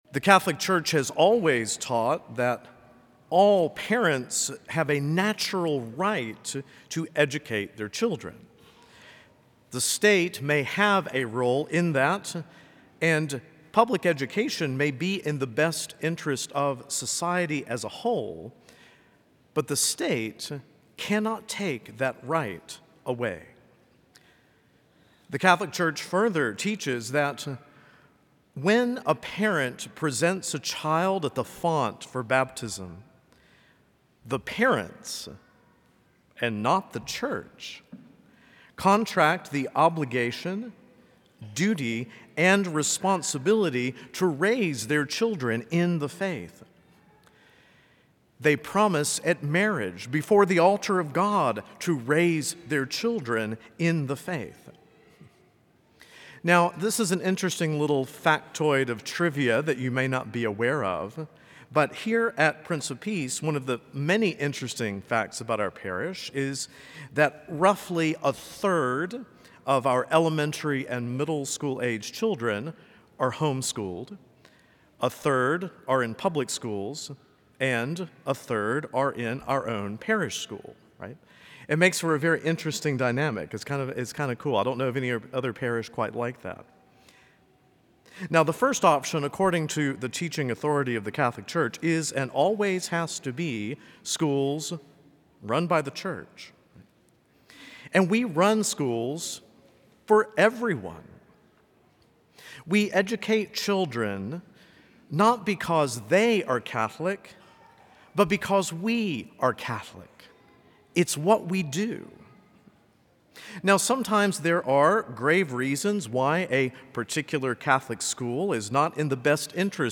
A homily from the series "Homilies."